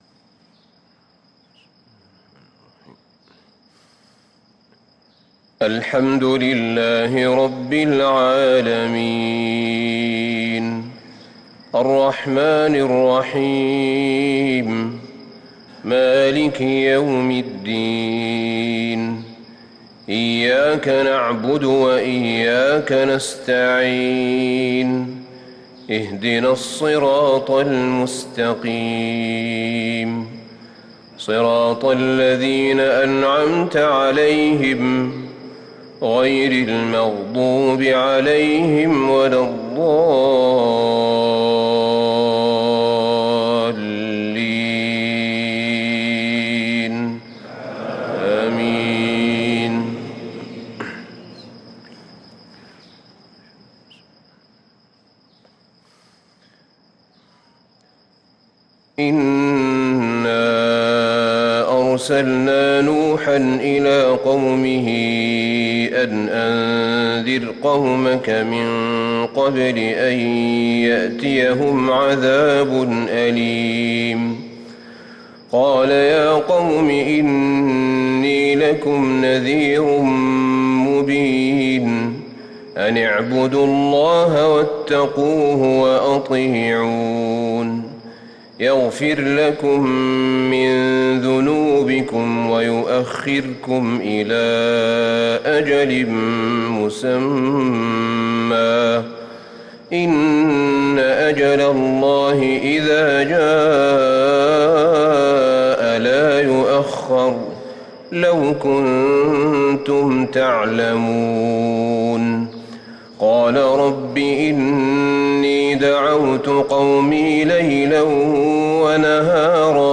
صلاة الفجر للقارئ أحمد بن طالب حميد 13 ذو القعدة 1444 هـ
تِلَاوَات الْحَرَمَيْن .